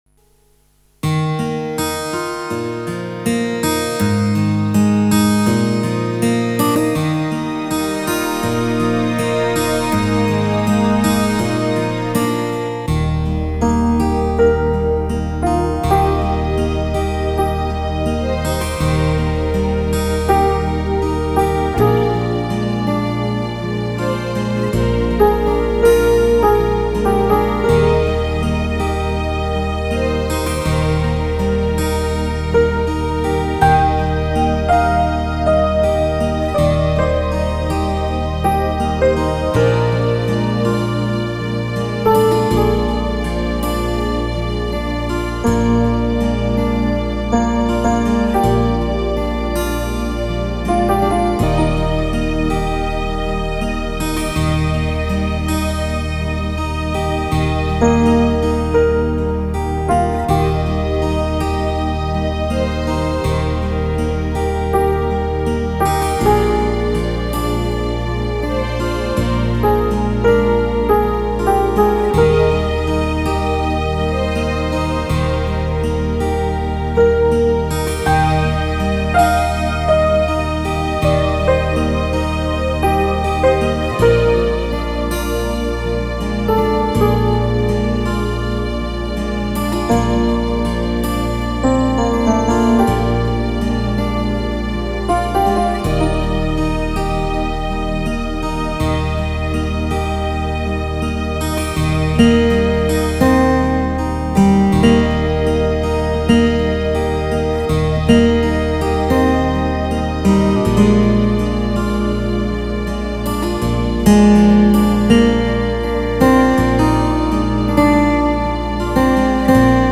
Flauto di Pan a aa strumenti musicali
MUSICA CLASSICA